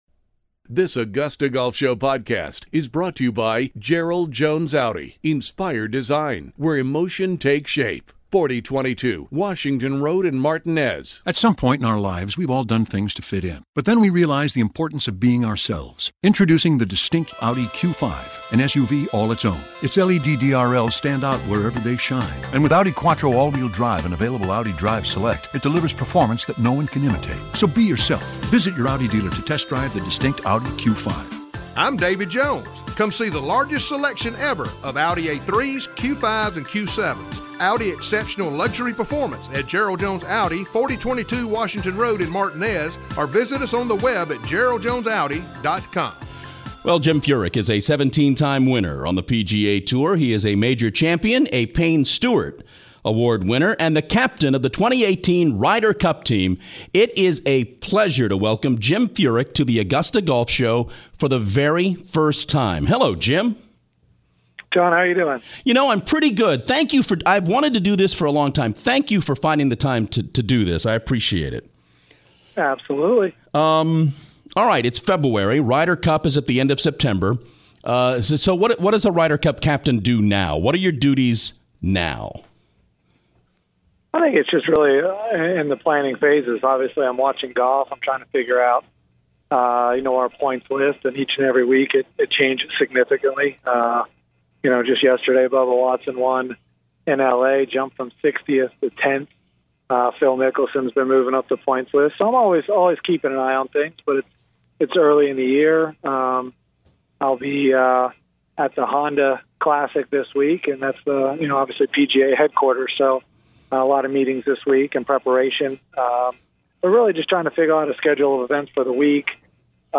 Jim Furyk, the 2018 Ryder Cup captain is on the show for the first time, talking about getting his 2018 season started after a wrist injury